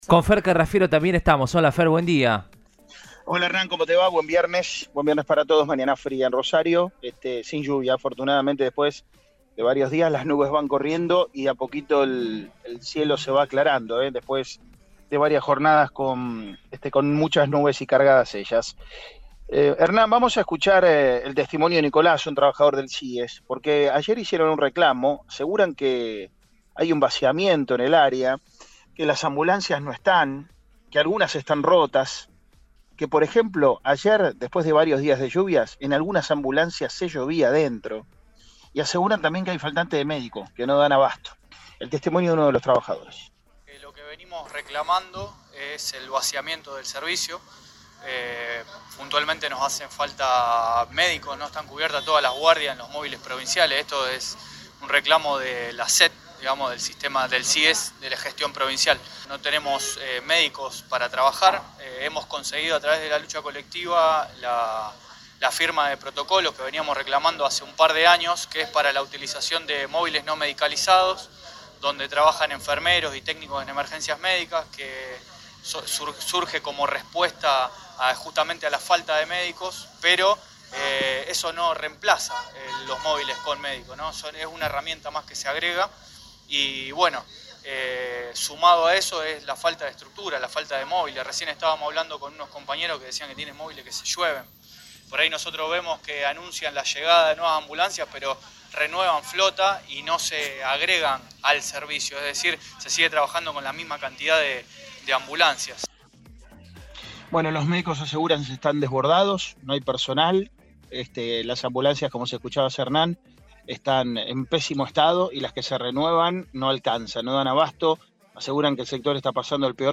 uno de los empleados del sector